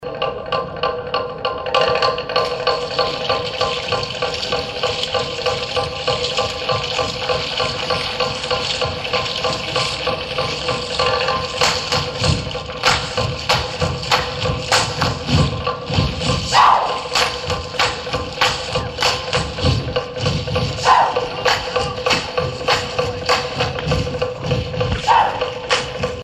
Représentation donnée en l'honneur des visiteurs